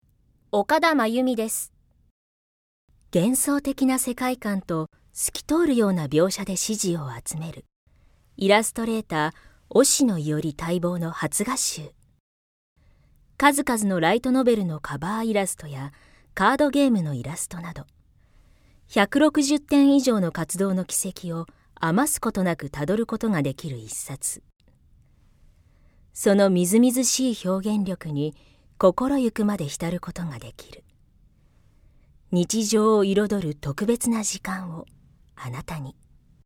◆画集CM(ソフト)◆